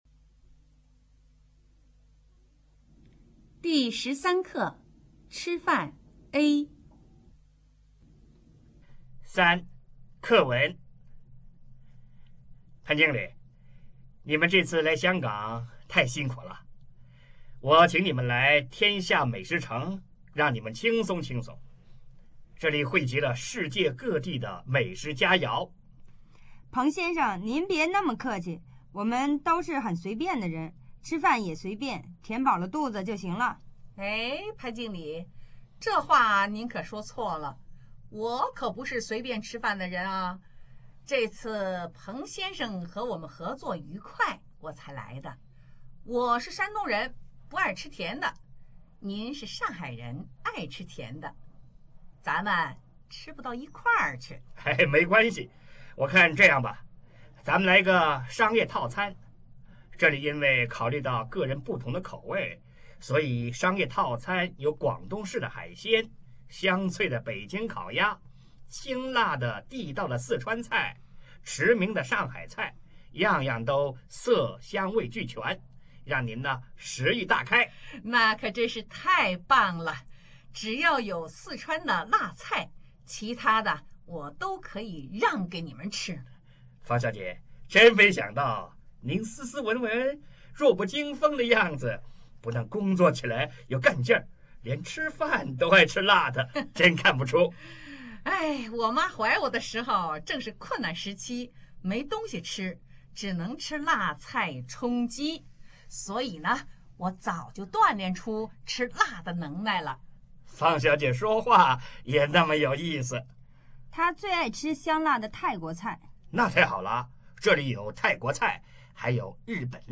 Text13_CONV.mp3